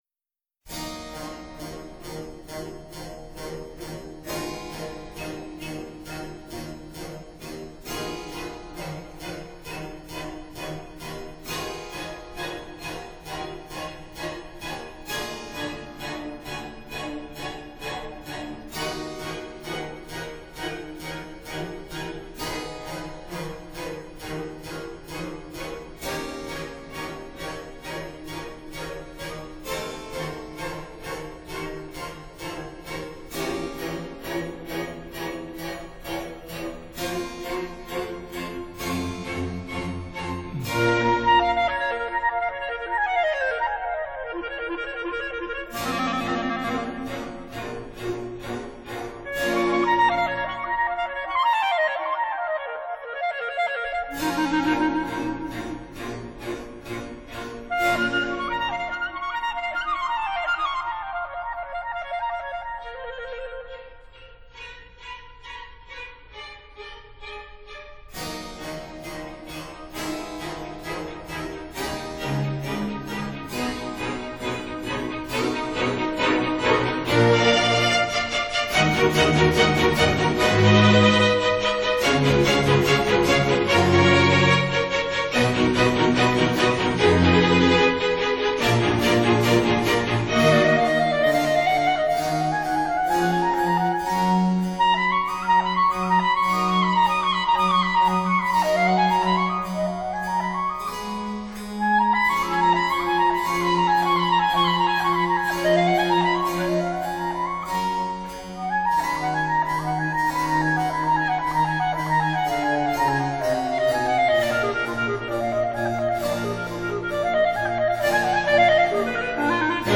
【古典爵士】
(Jazz Quartet & Chamber Orchestra)
Genre: Jazz